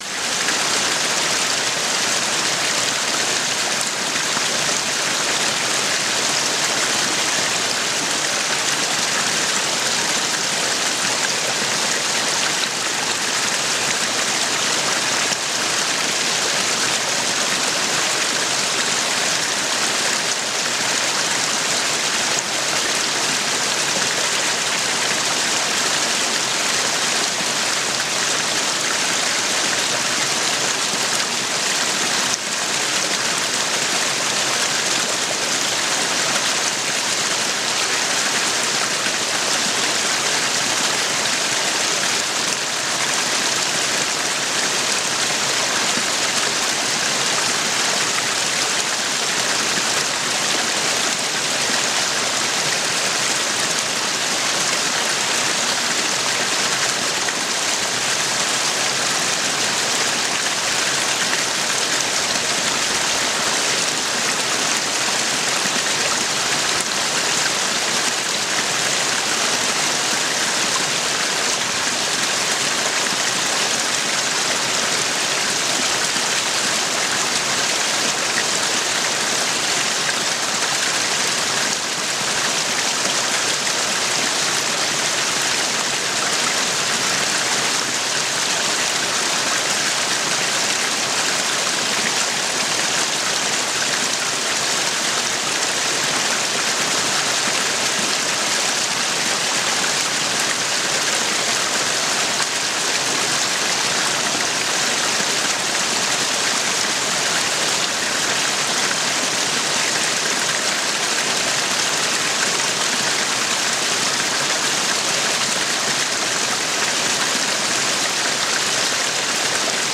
PERFEKTE HARMONIE: Bergbach-Vogelvereinigung mit Wald-Stimmen